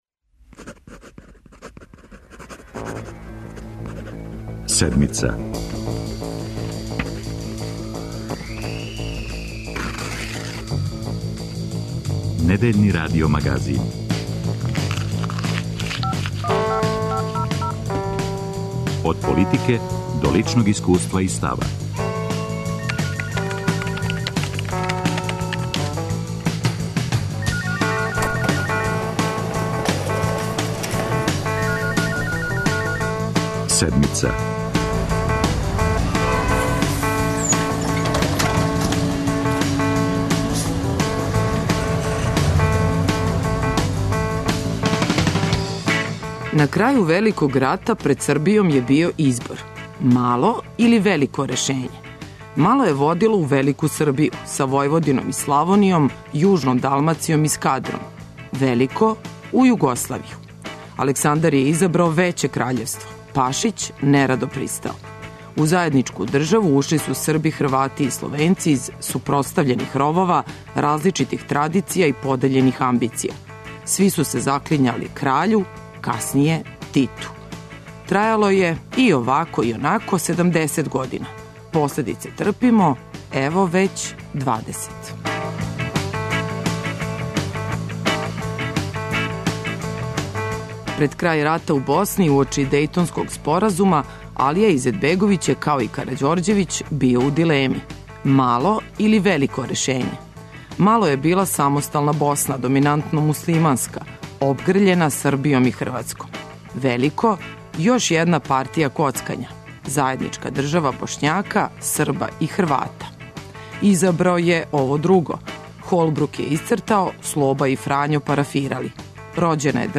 Шта боли у речима председника Томислава Николића? О федерацији БиХ, односима са Србијом и неспоразумима са Републиком Српском ексклузивно за Седмицу говори члан председништва Босне и Херцеговине Бакир Изетбеговић.